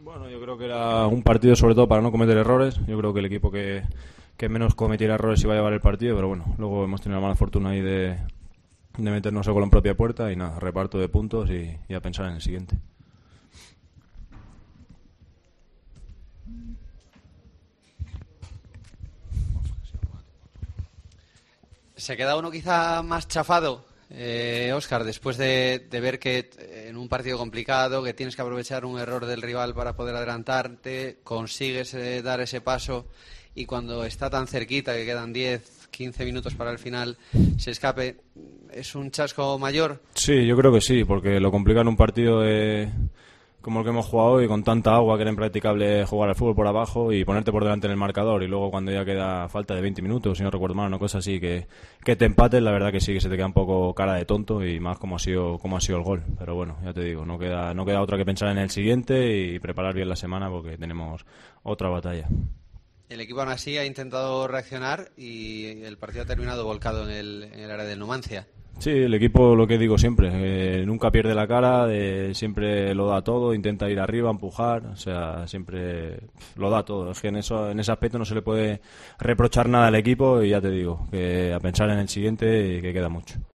AUDIO: Escucha aquí la centrocampista de la Ponferradina tras el empate 1-1 ante el Numancia